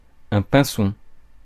Ääntäminen
Ääntäminen France: IPA: [pɛ̃.sɔ̃] Haettu sana löytyi näillä lähdekielillä: ranska Käännös Konteksti Substantiivit 1. finch eläintiede 2. chaffinch Suku: m .